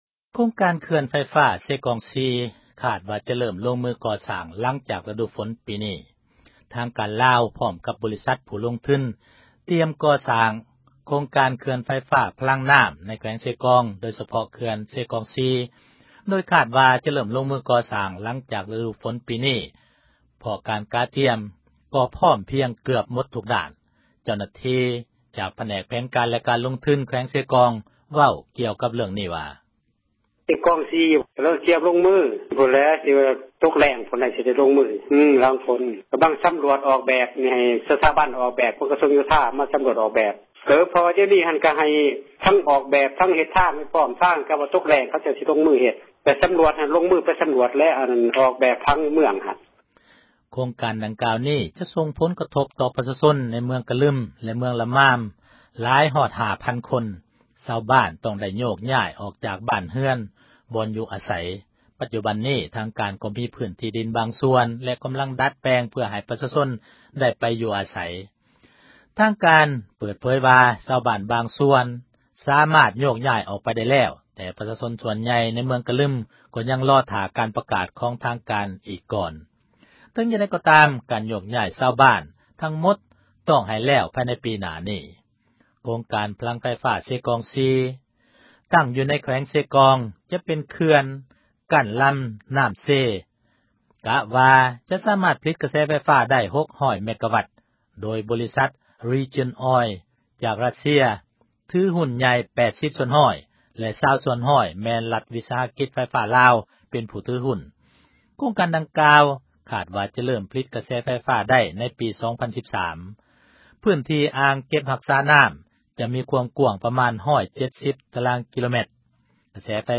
ເຈົ້າໜ້າທີ່ຜແນກ ແຜນການ ແລະການລົງທຶນ ແຂວງເຊກອງ ເວົ້າກ່ຽວກັບ ເຣື້ອງນີ້ວ່າ: